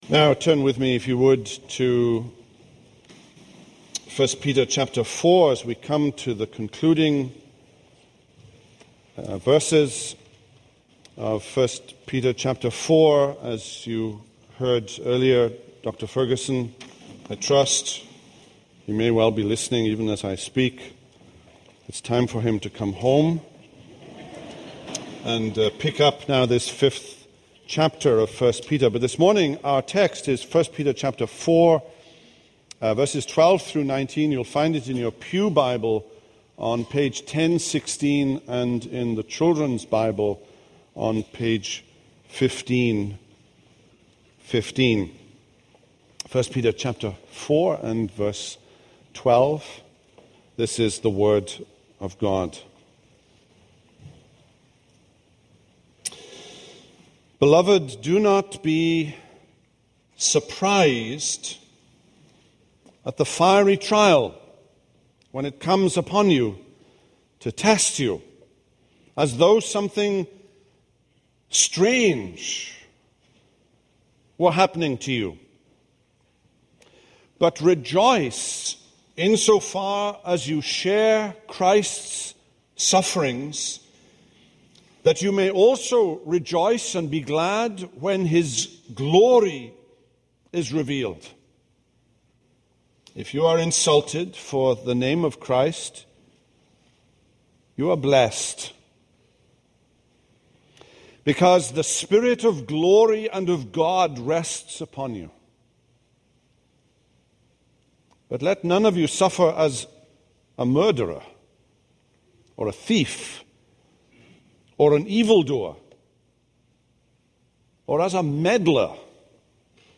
This is a sermon on 1 Peter 4:12-19.